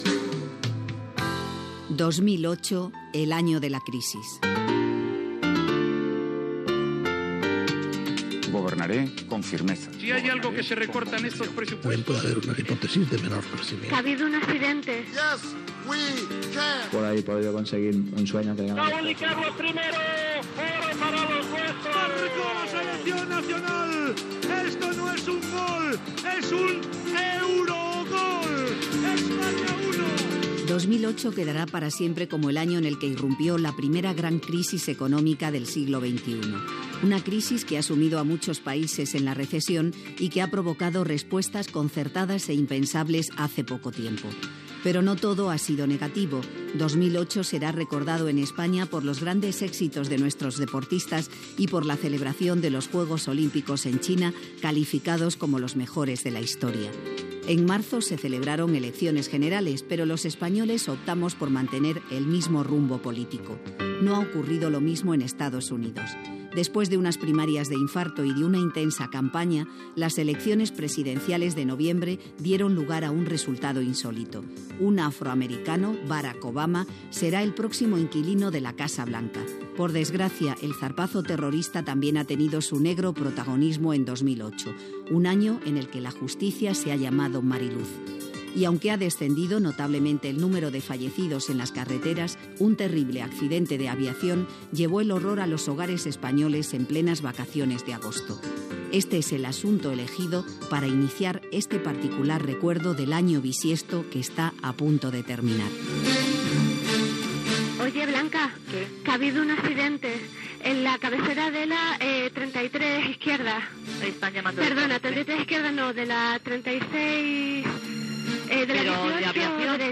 Identificació del programa, resum de veus i de temes informatius significatius de l'any. L'accident del vol de Spainair JK5022 a l'aeroport de Madrid, eleccions generals espanyoles
Informatiu
FM